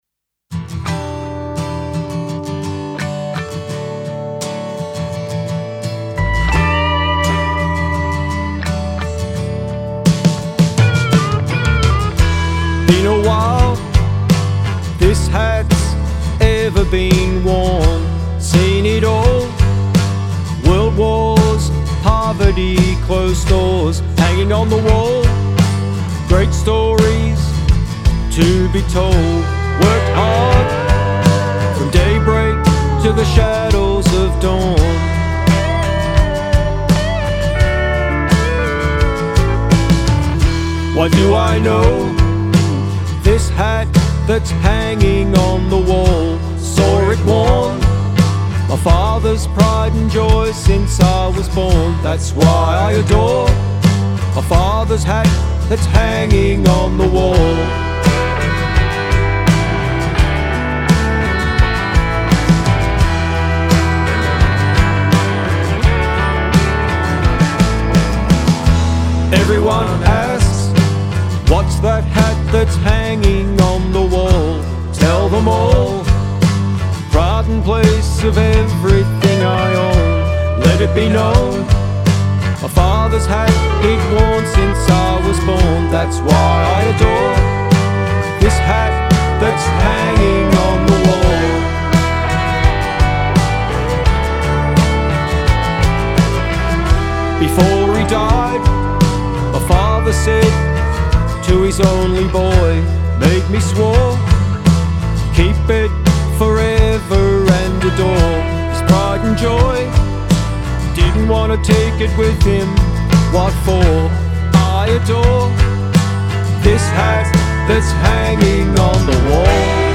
melancholic tune
serene guitars
pedal steel
was recorded at Mountain Sound Studio